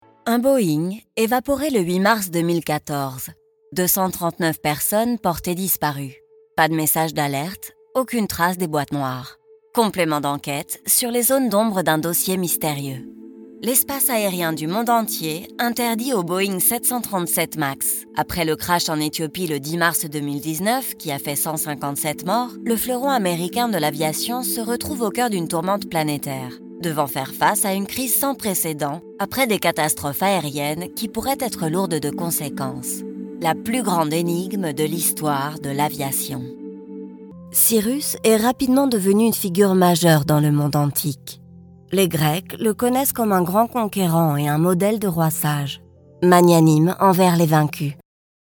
Documentaries
My voice is flexible and adaptable.
Home Studio Setup
Microphone : RODE NT1-A